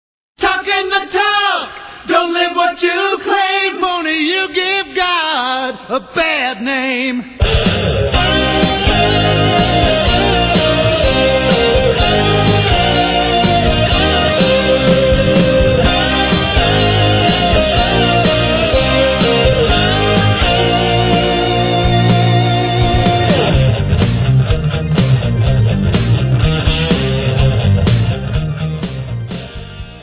Christian lyrics written to the tune of popular songs
You will love the upbeat music and fun Christian message.